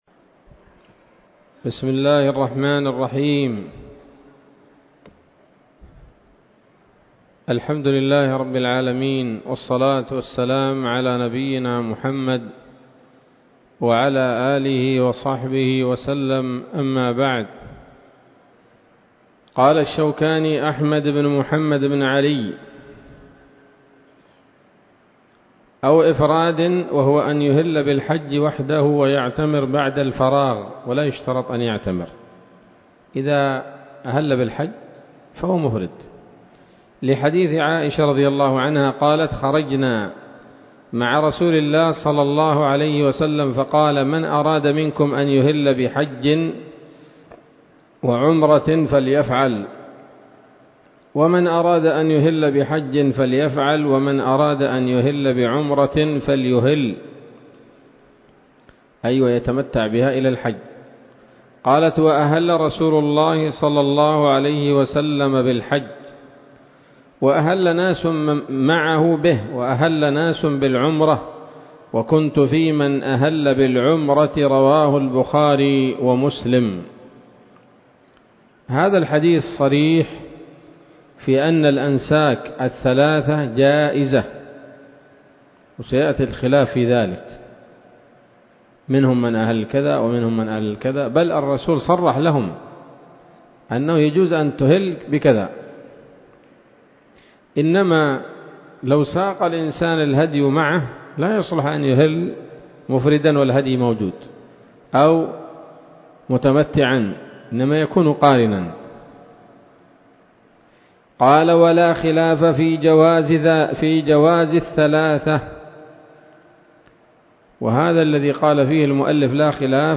الدرس الرابع من كتاب الحج من السموط الذهبية الحاوية للدرر البهية